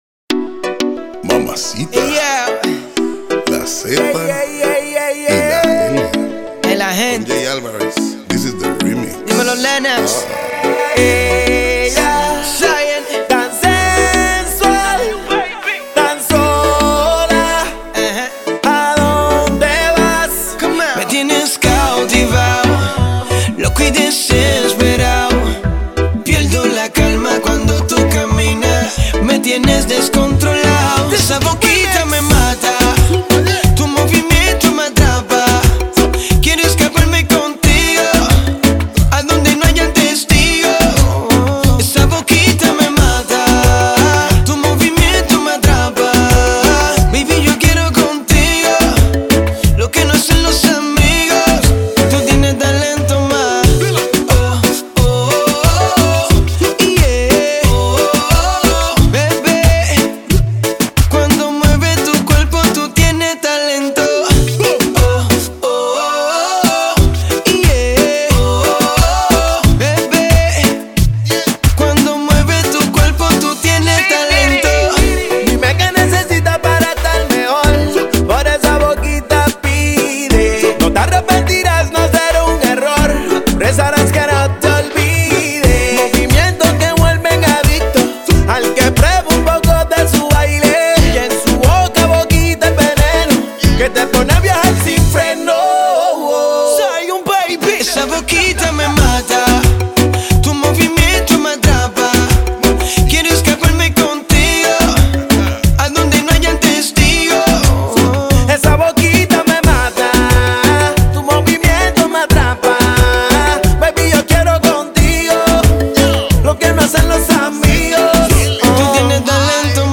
зажигательный трек в жанре реггетон